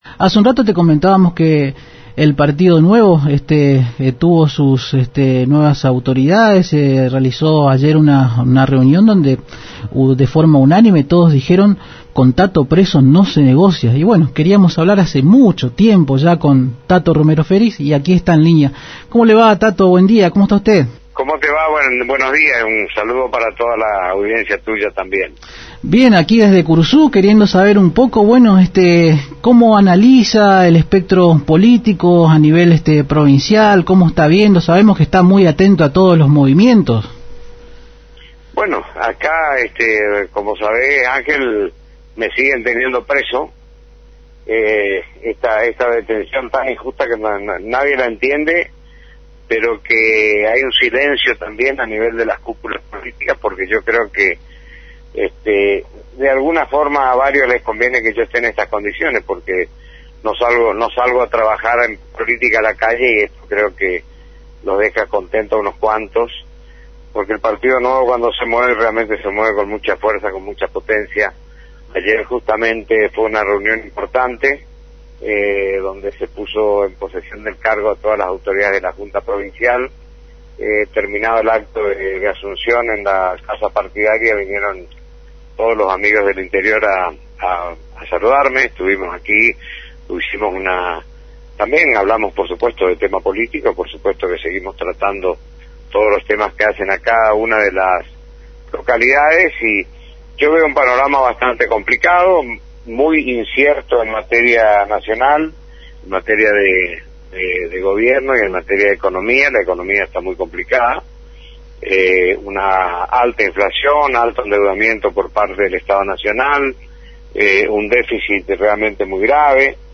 En contacto con Arriba Ciudad a través de la AM 970 Radio Guaraní el líder nuevista analizó el espectro político provincial y nacional y envió un afectuoso saludo a la comunidad curuzucuateña en vísperas de las fiestas.